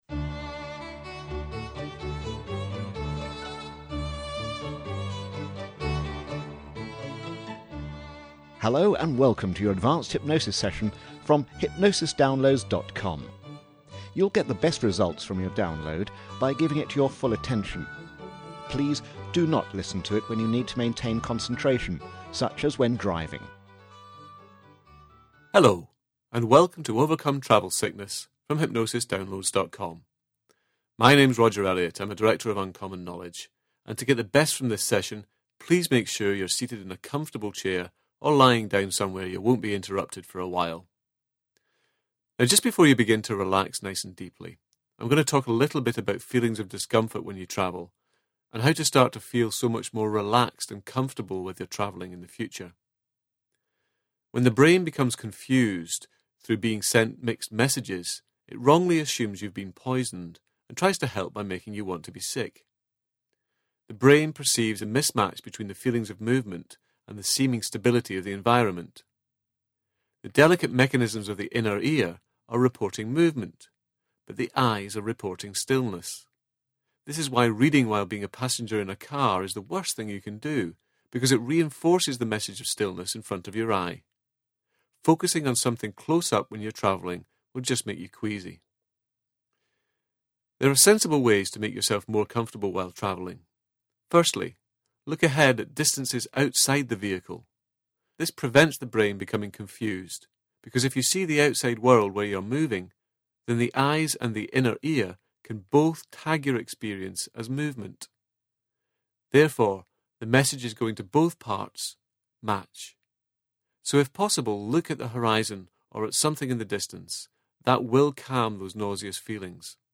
Hypnosis (1/2)